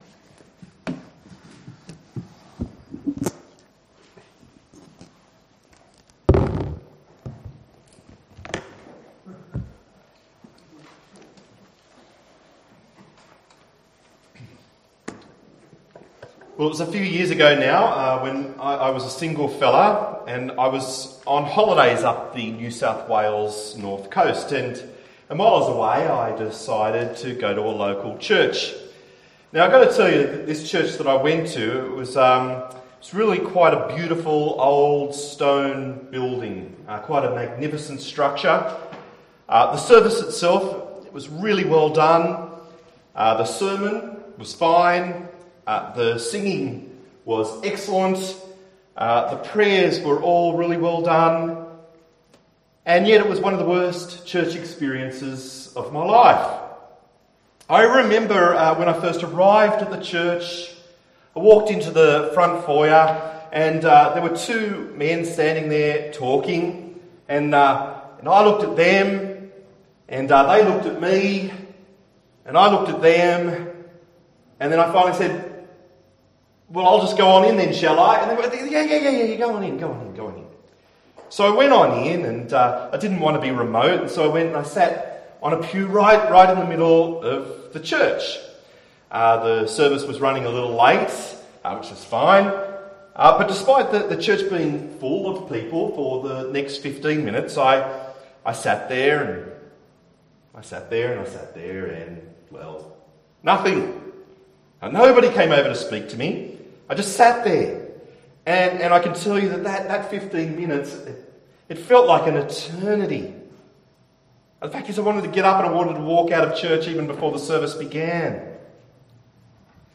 Passage: Psalm 133:1-3 Service Type: WPC Camp 2018